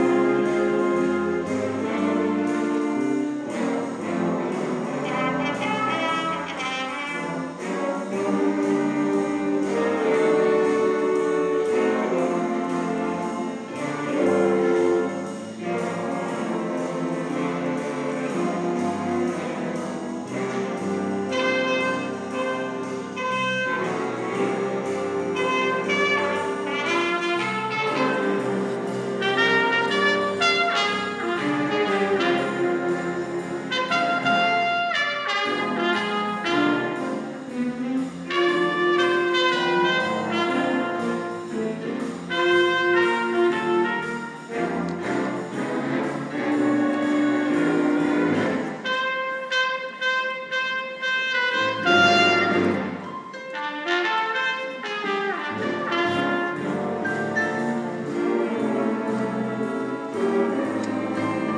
Reading MS jazz band
A little jazz to start the day at the Blue Ribbon Schools conference..toe tapping!